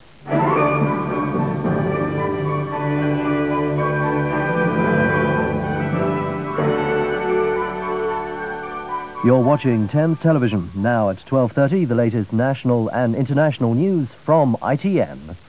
Another Thames jingle followed by a continuity announcement, this time from the 1990s. Notice how the jingle resembles the ITV generic logo jingle.
thames_90s.wav